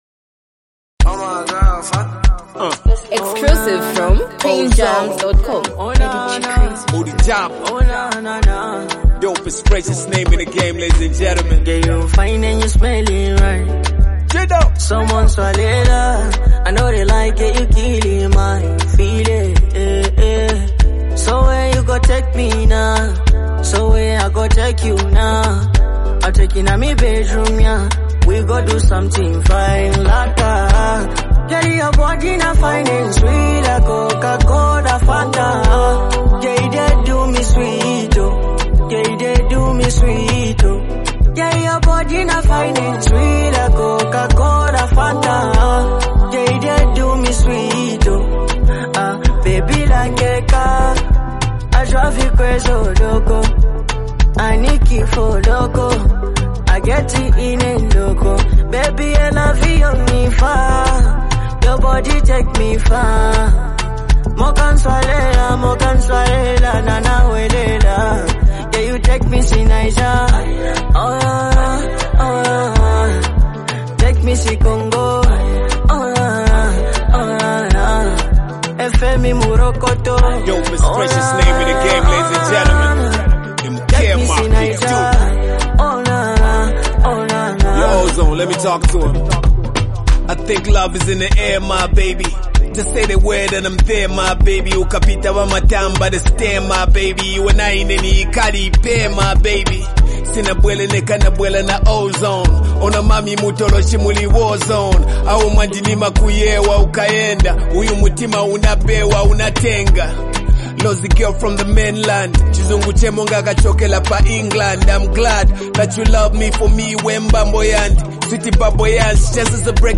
soulful love song